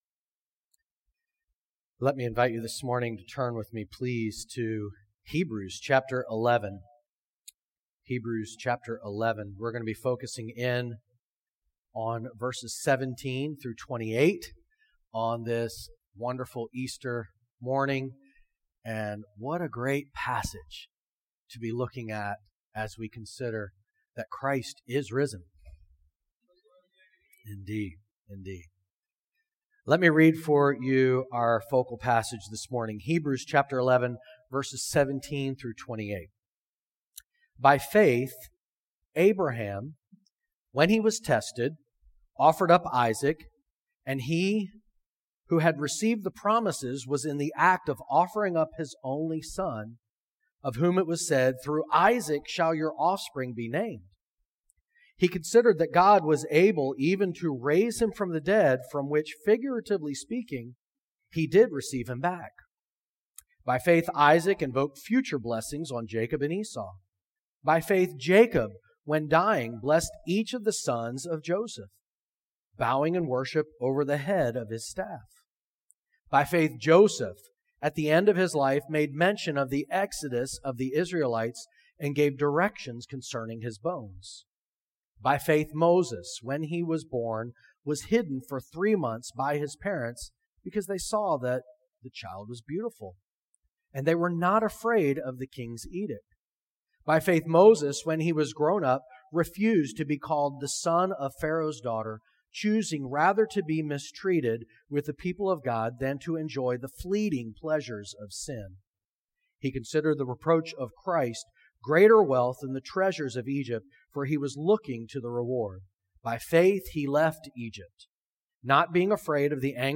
Sermons | Great Commission Baptist Church